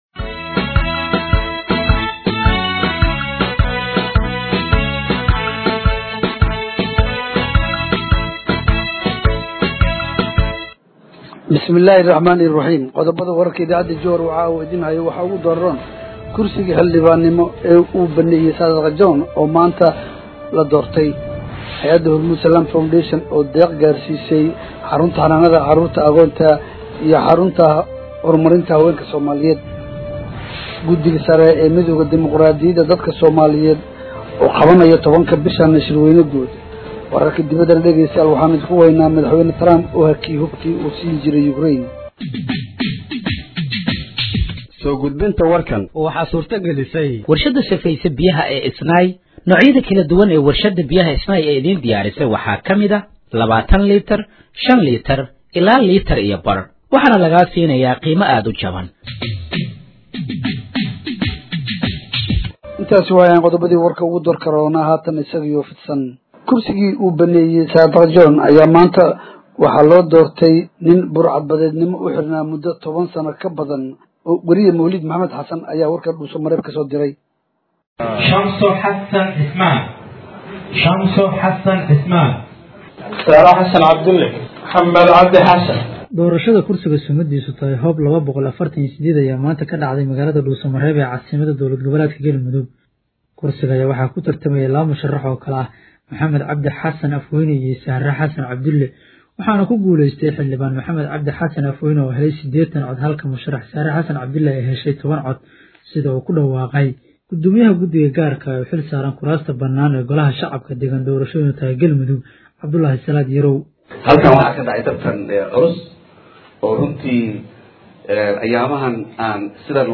Dhageeyso Warka Habeenimo ee Radiojowhar 04/03/2025
Halkaan Hoose ka Dhageeyso Warka Habeenimo ee Radiojowhar